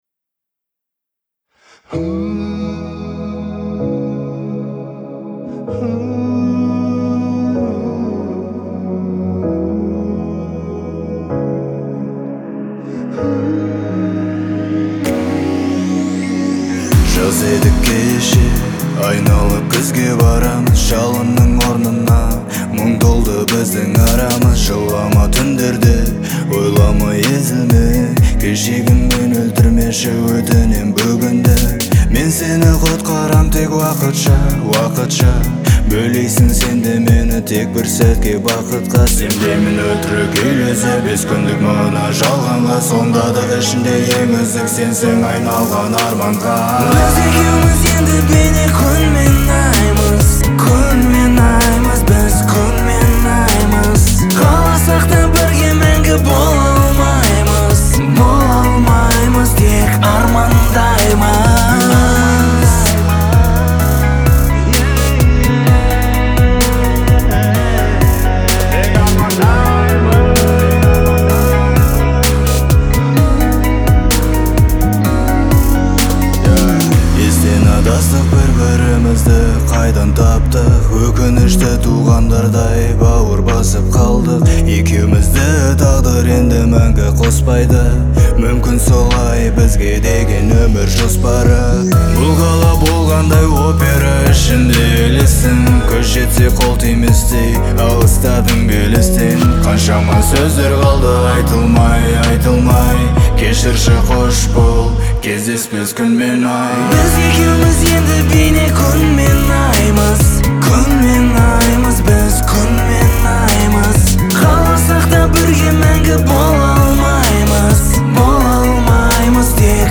это яркая и эмоциональная композиция в жанре хип-хоп